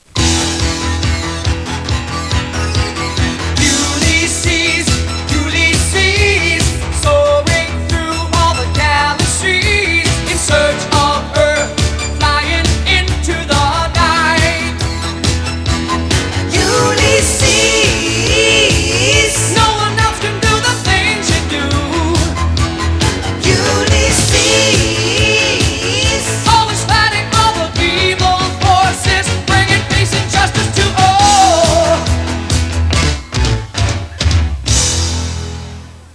end theme music